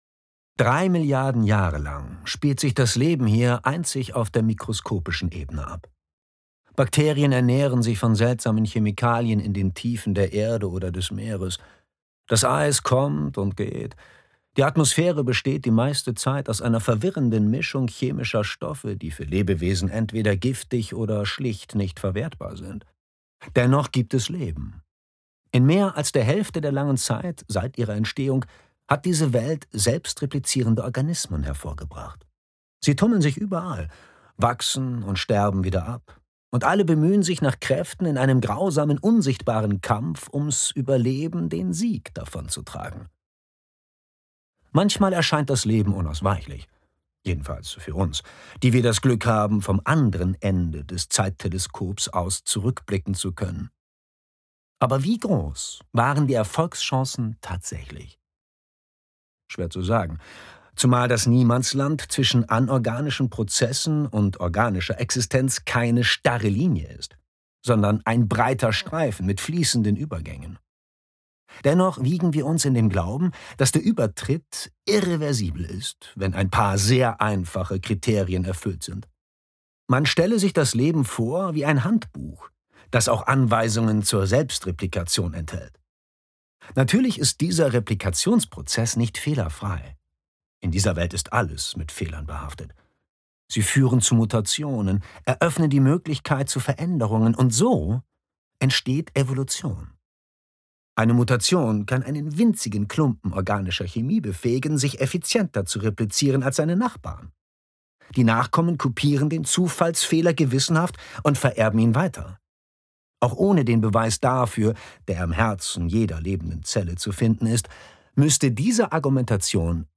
2022 | Originalfassung, ungekürzt